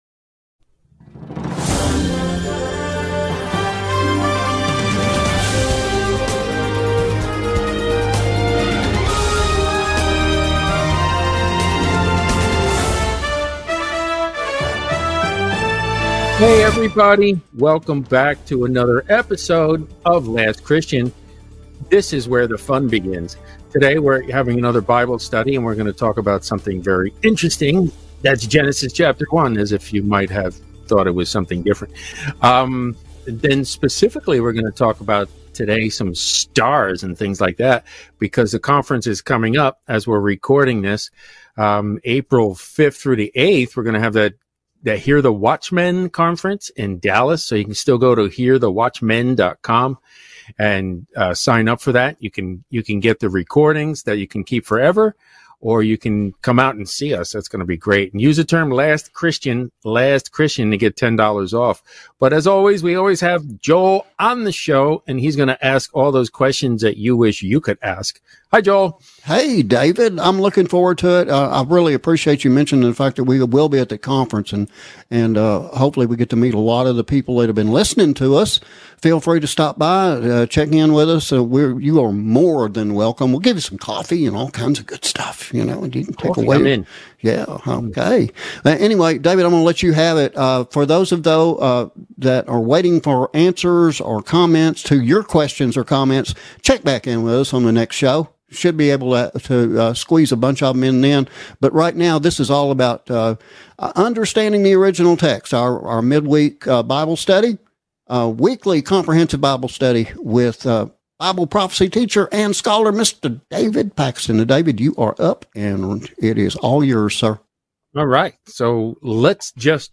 MidWeek Comprehensive Bible Study